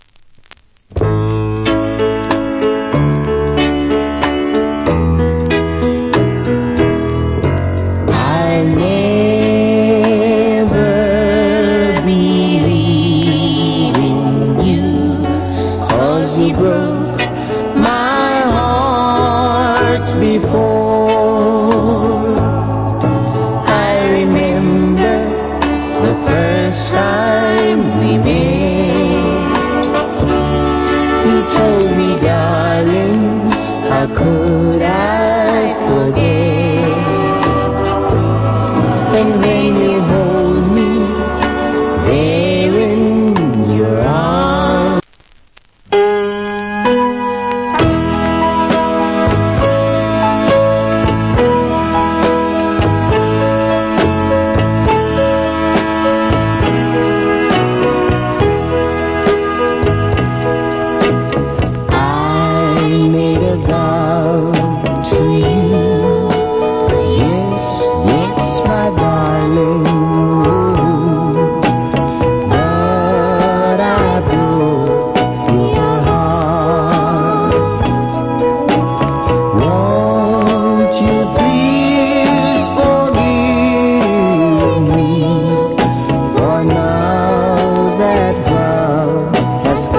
Great Duet Ballad Vocal.